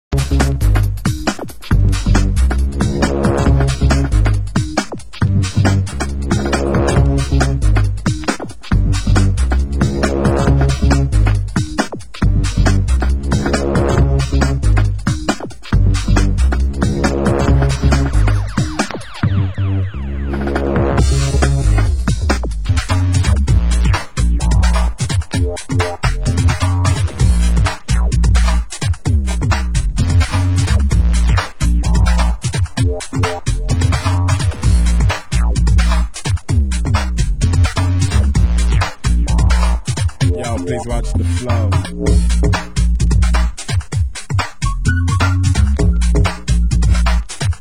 Genre: UK Garage